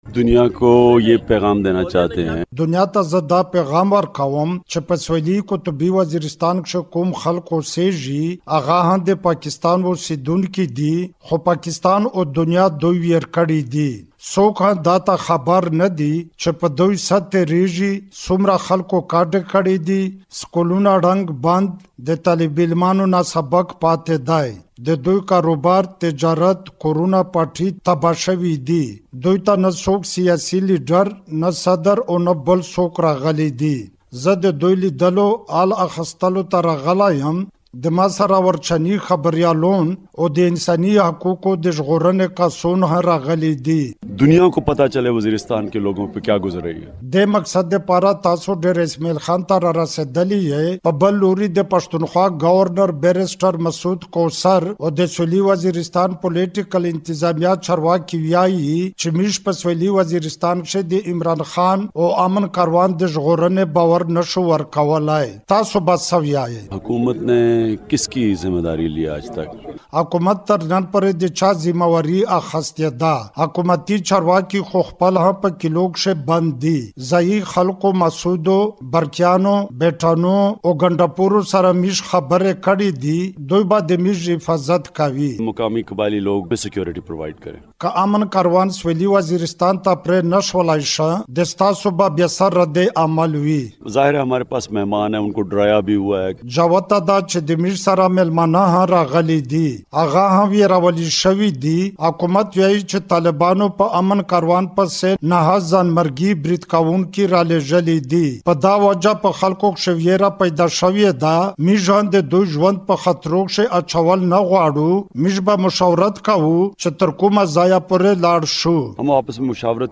د مشال مرکه
عمران خان سره د مشال راډیو ځانګړې مرکه